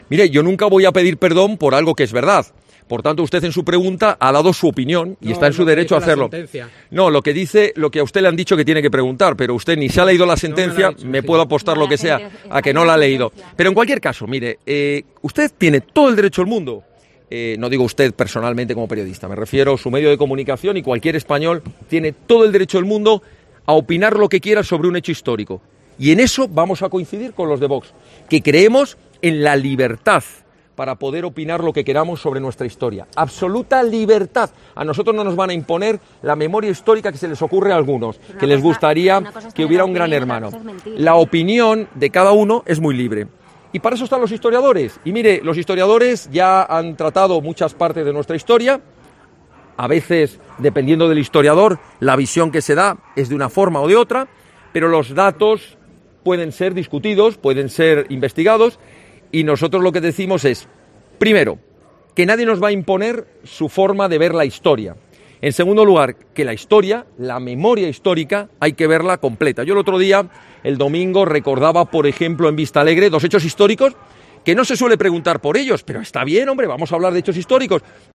Ortega Smith, hizo estas declaraciones a las puertas de la nueva sede de VOX en Zamora, y eludió las insistentes preguntas de los periodistas sobre si “eran violadoras y asesinas las niñas”, momento de tensión que fue acallado con aplausos de los afiliados y simpatizantes .